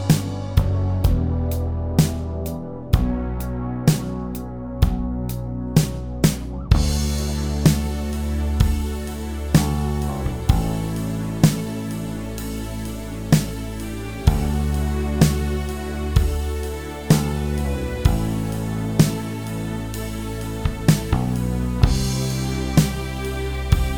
no Backing Vocals Rock 4:52 Buy £1.50